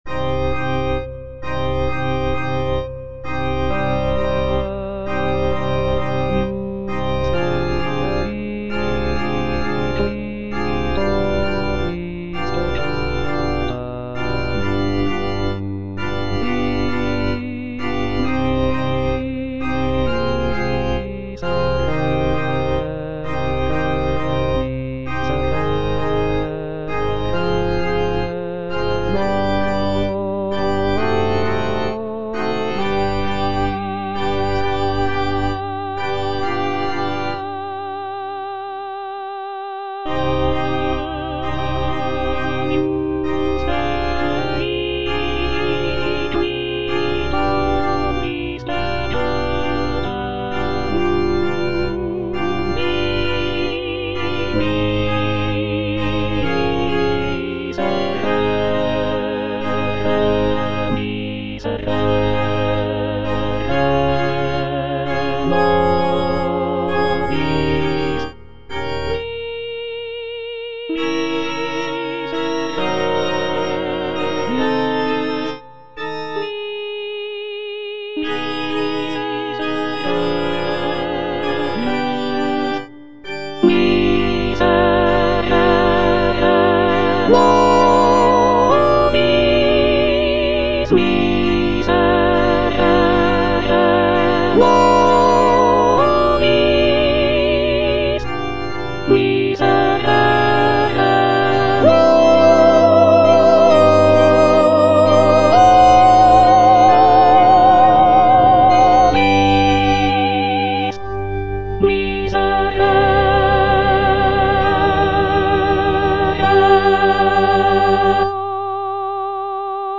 C.M. VON WEBER - MISSA SANCTA NO.1 Agnus Dei - Soprano (Emphasised voice and other voices) Ads stop: auto-stop
"Missa sancta no. 1" by Carl Maria von Weber is a sacred choral work composed in 1818.
The work features a grand and powerful sound, with rich harmonies and expressive melodies.